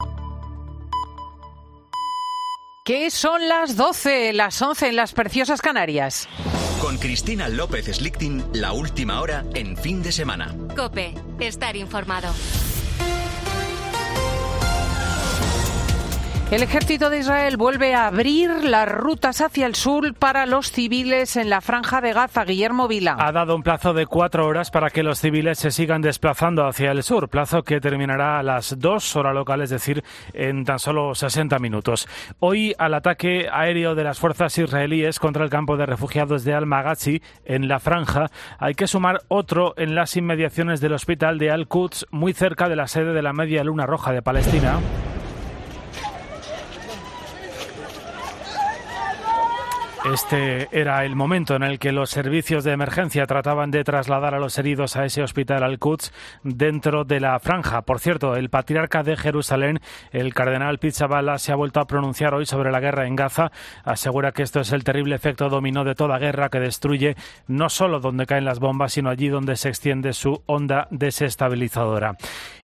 Este era el momento en el que los servicios de emergencia trataban de trasladar a los heridos a ese Hospital Al Quds, dentro de la Franja de Gaza.